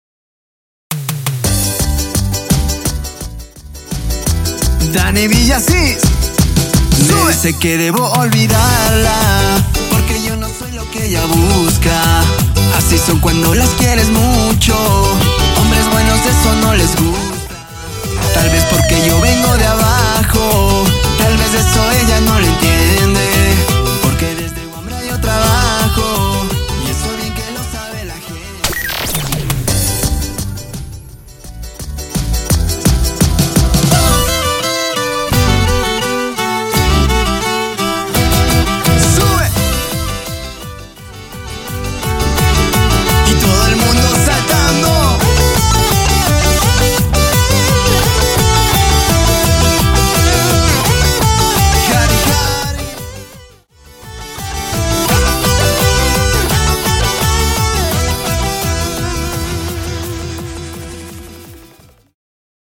Folklore Electro